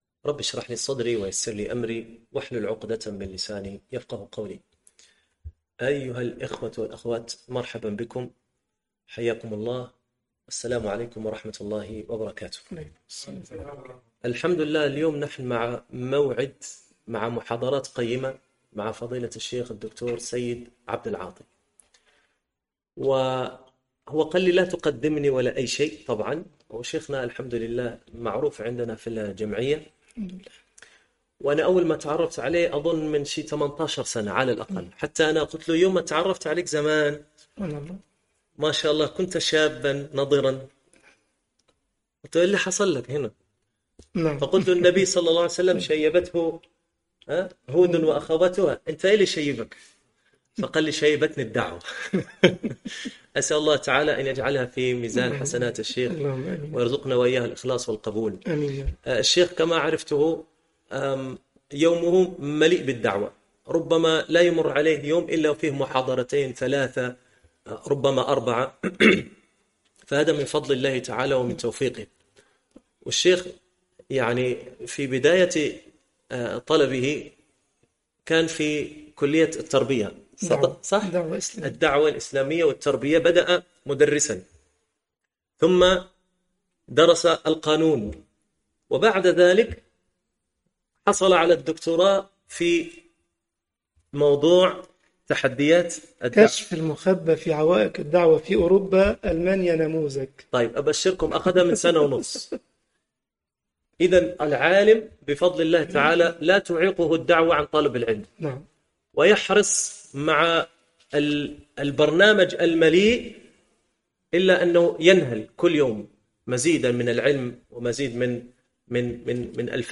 المحاضرة 1